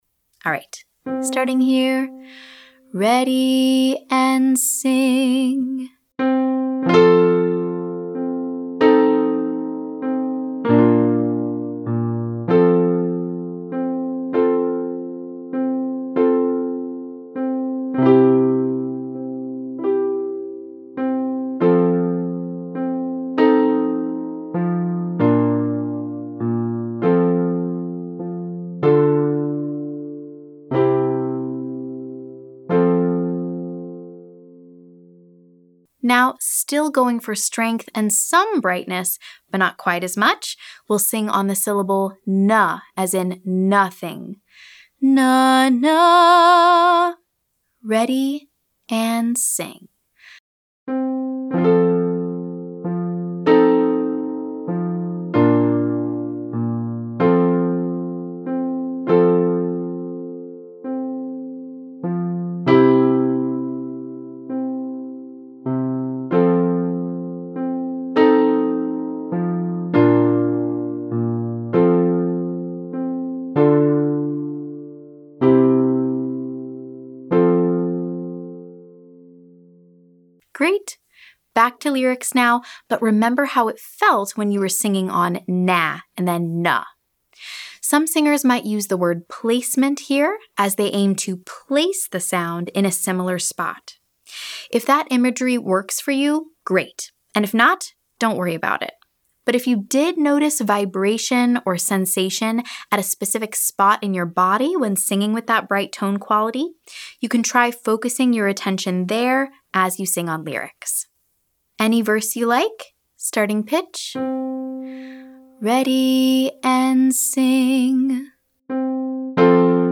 Bright & “Bratty” Sounds - Online Singing Lesson
• Sing the line using the bright “bratty” sound.
Repeat this line several times, aiming for a clear and bright tone in the head voice.